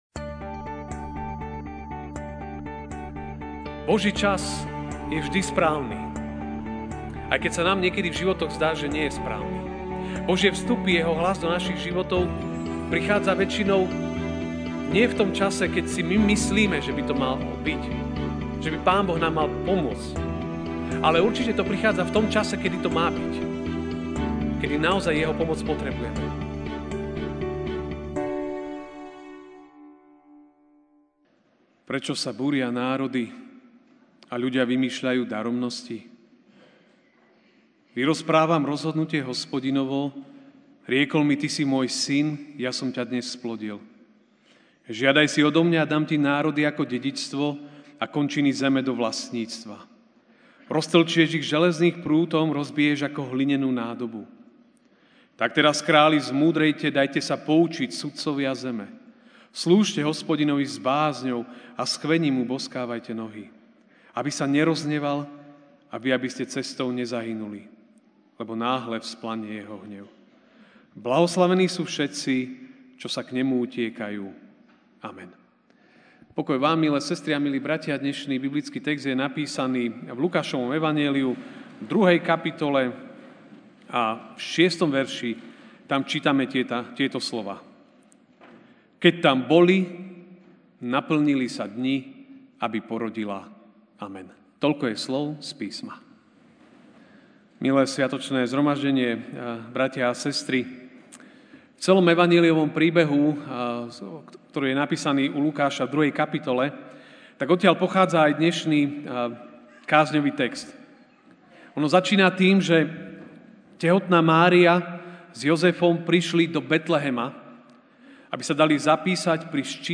Štedrovečerná kázeň